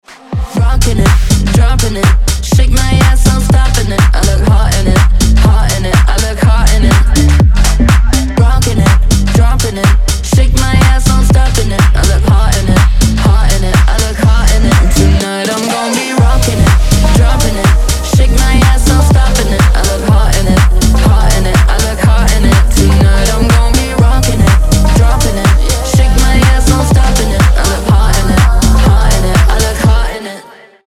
женский голос
Tech House